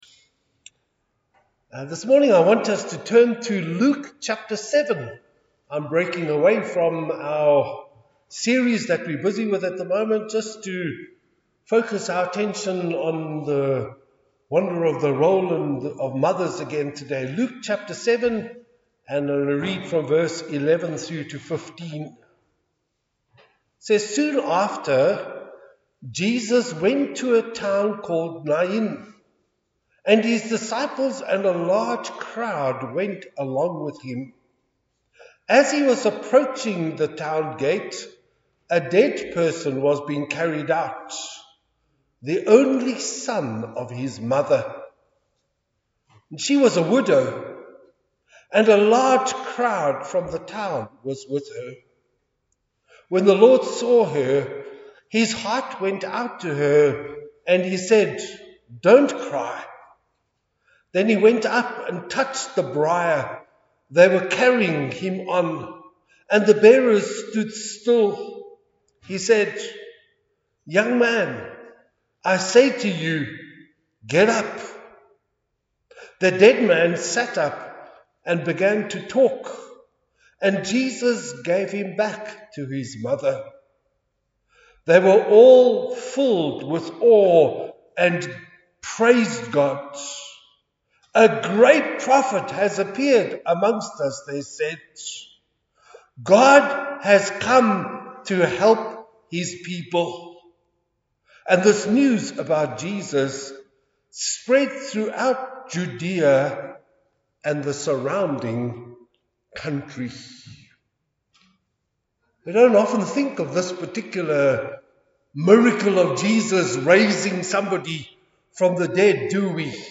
Passage: Jeremiah 12:1-12 Service Type: Sunday Service